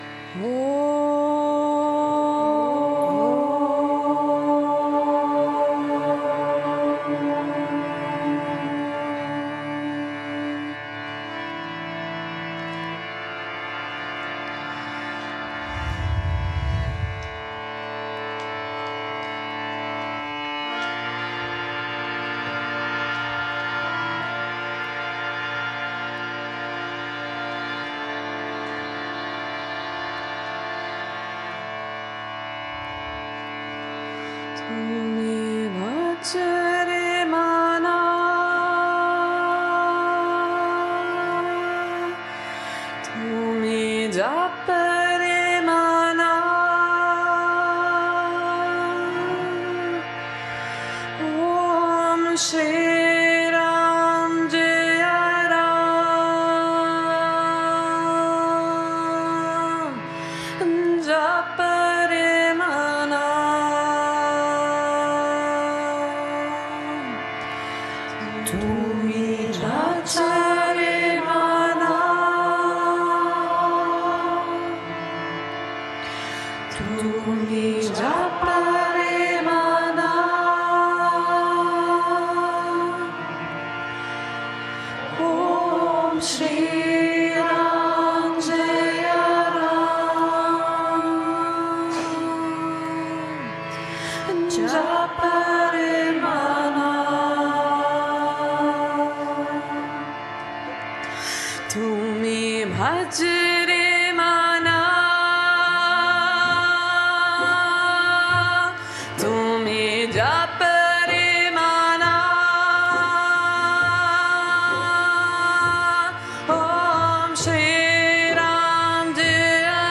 Mantra
Hier der volle Text in vereinfachter Umschrift: Tumi Bhaja Re Mana, Tumi Japa Re Mana Om Shri Ram Jaya Ram, Japa Re Mana „Tumi Bhaja Re Mana“ ist ein Kirtan, der bei Yoga Vidya sehr populär ist, auch in…